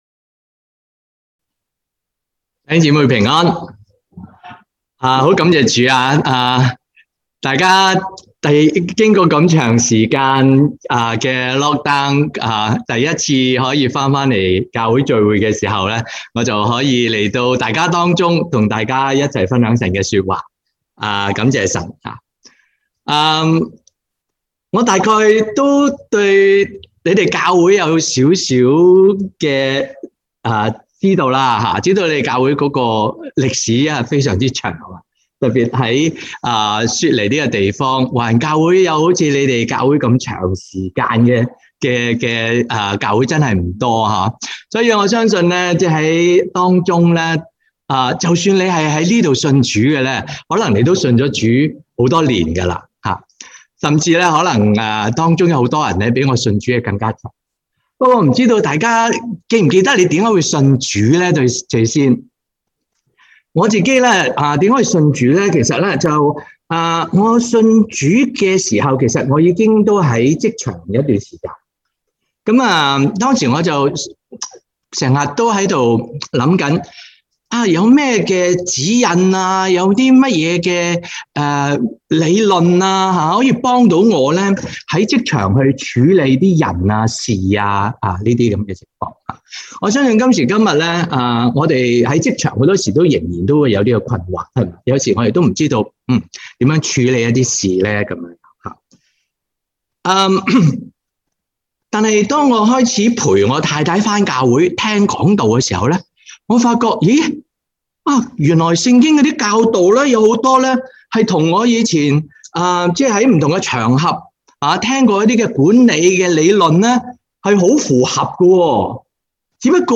sermon1017.mp3